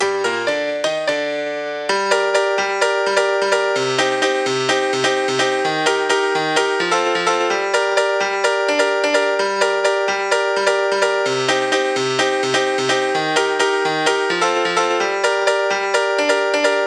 VTDS2 Song Kit 10 Male Going Crazy Piano.wav